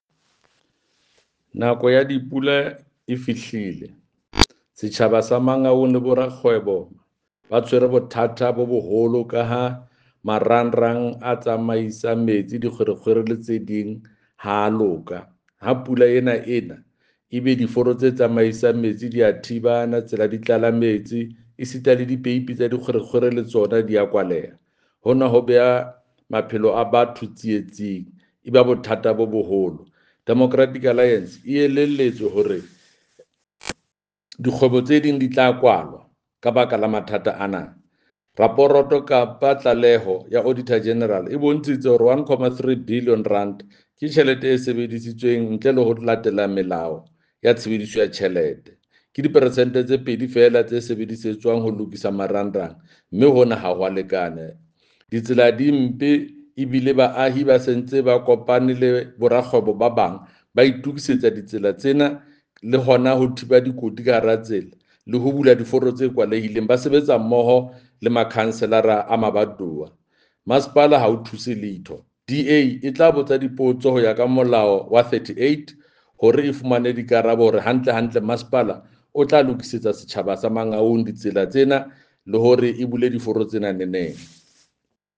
Sesotho soundbite by Jafta Mokoena MPL and images here, here, here, here, and here